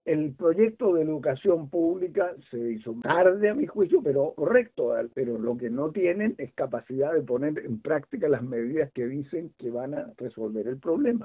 En conversación con La Radio, el exministro de Educación de Ricardo Lagos, Sergio Bitar, valoró avances del gobierno en materia de educación pública con la entrada de los Slep, pero criticó la capacidad de ejecución del gobierno.